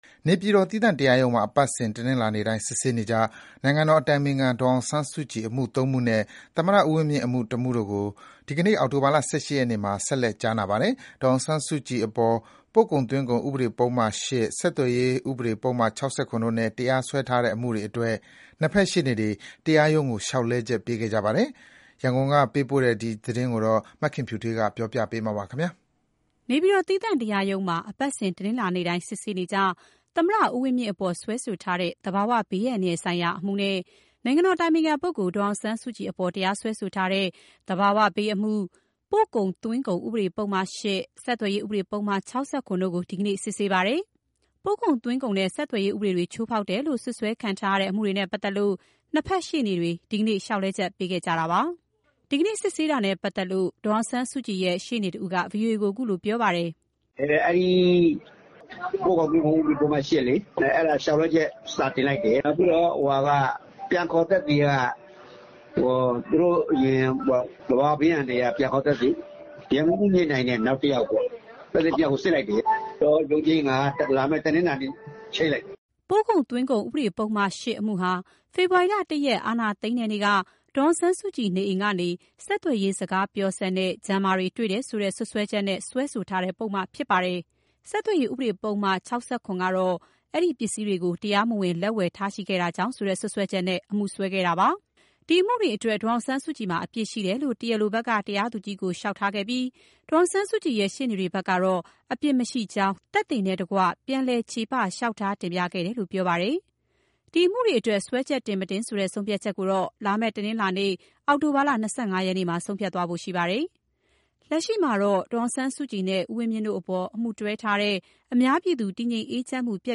ရန်ကုန်က ပေးပို့ထားတဲ့သတင်း
နေပြည်တော် သီးသန့်တရားရုံးမှာ အပတ်စဉ်တနင်္လာနေ့တိုင်း စစ်ဆေးနေကျ သမ္မတဦးဝင်းမြင့်အပေါ် စွဲထားတဲ့ သဘာဝဘေးအန္တရာယ်ဆိုင်ရာအမှုနဲ့ နိုင်ငံတော်အတိုင်ပင်ခံ ဒေါ်အောင်ဆန်းစုကြည်အပေါ် တရားစွဲထားတဲ့ သဘာဝဘေးအမှု၊ ပို့ကုန်သွင်းကုန်ဥပဒေ ပုဒ်မ ၈၊ ဆက်သွယ်ရေးဥပဒေပုဒ်မ ၆၇ တို့ကို ဒီကနေ့စစ်ဆေးပါတယ်။ ပို့ကုန်သွင်းကုန်နဲ့ ဆက်သွယ်ရေး ဥပဒေတွေချိုးဖောက်တယ်လို့ စွပ်စွဲခံထားရတဲ့ အမှုတွေနဲ့ပတ်သက်လို့၂ ဘက်ရှေ့နေတွေ ဒီနေ့ လျှာက်လဲချက်ပေးခဲ့ကြပါတယ်။ ဒီကနေ့ စစ်ဆေးတာနဲ့ပတ်သက်လို့ ဒေါ်အောင်ဆန်းစုကြည်ရဲ့ ရှေ့နေကြီးတဦးက ဗွီအိုအေကို အခုလို ပြောပြပါတယ်။